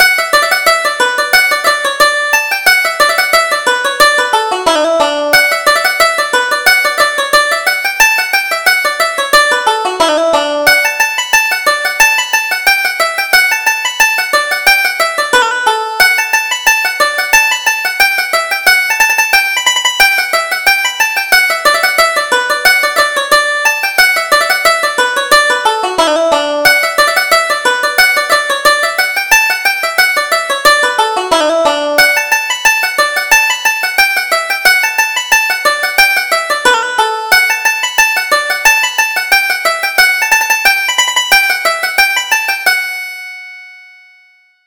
Reel: The Strawberry Blossom - 2nd Setting